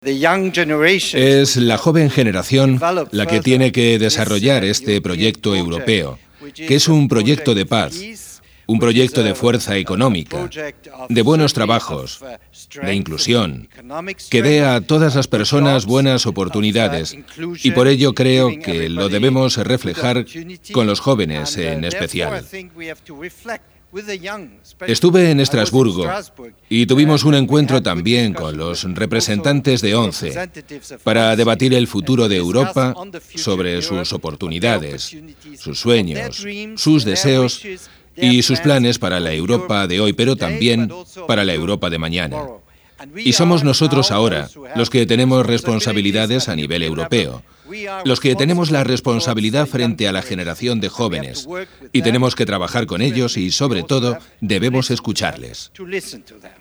manifestó Schmit formato MP3 audio(1,08 MB).